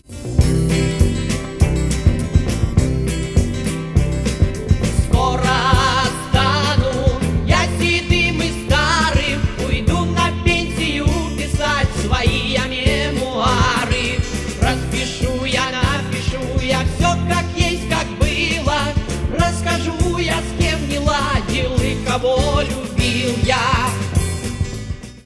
• Качество: 320, Stereo
мужской вокал